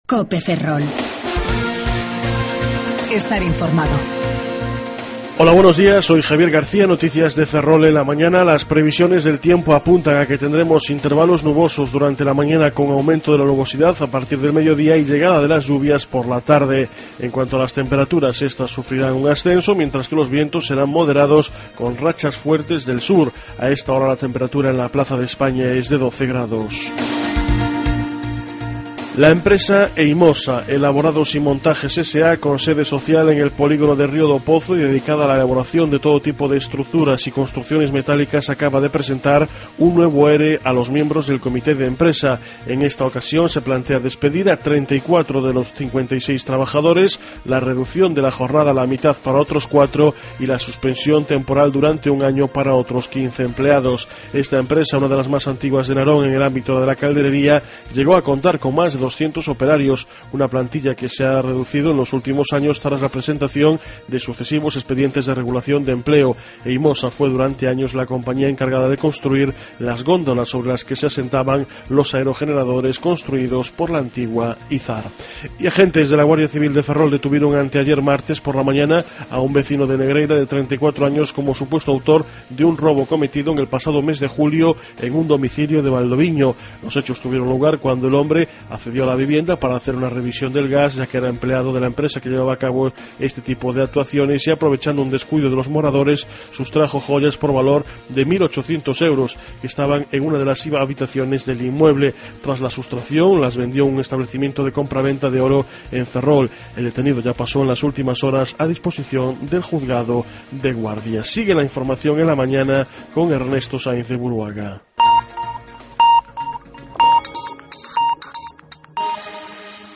07:58 Informativo La Mañana